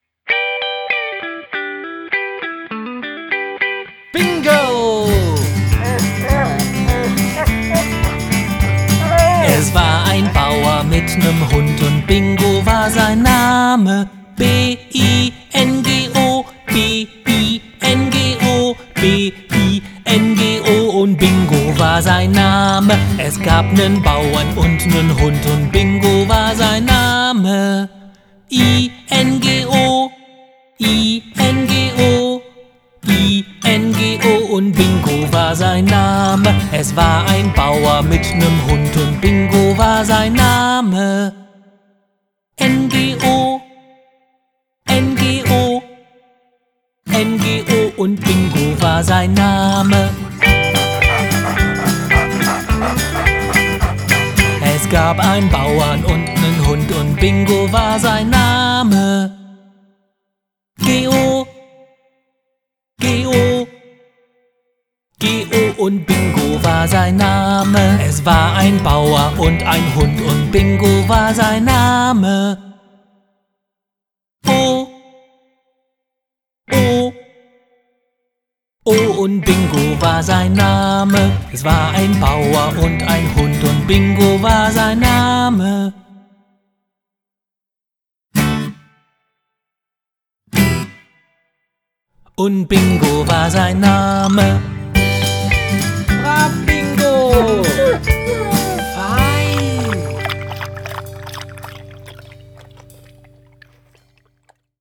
Lernlieder